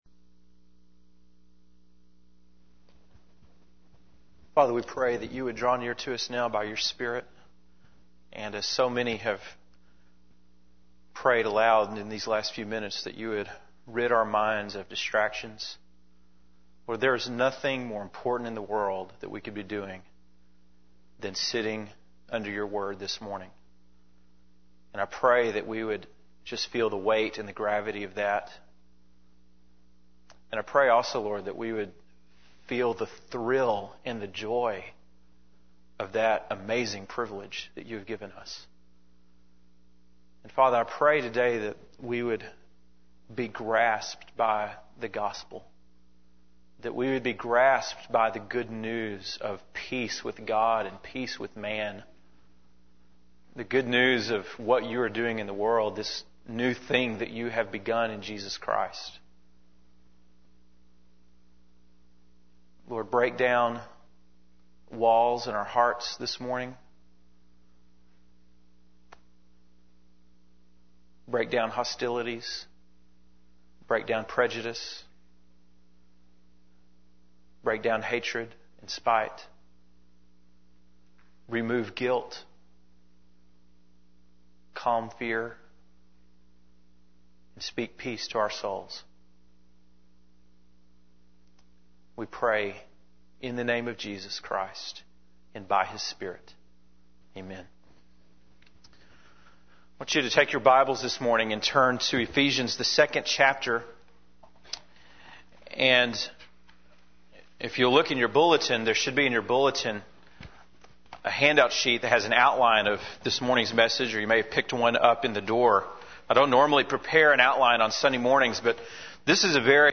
February 13, 2005 (Sunday Morning)